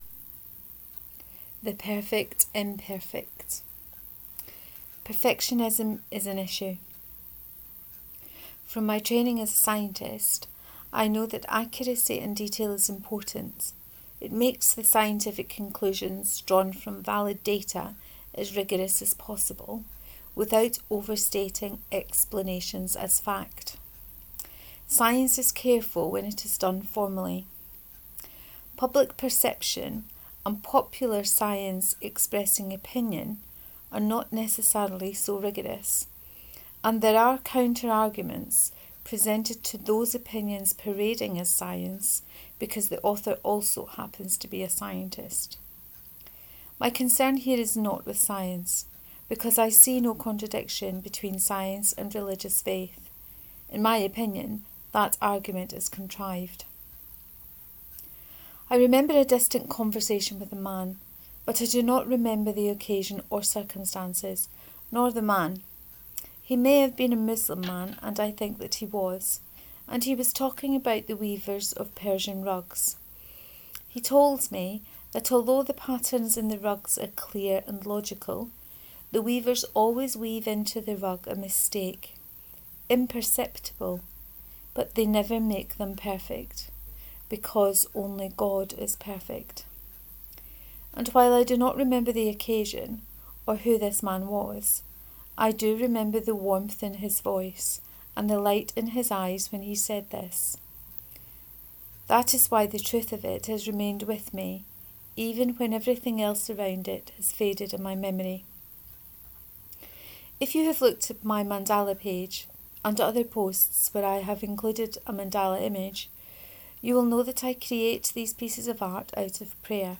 The Perfect Imperfect 1: Reading of this post.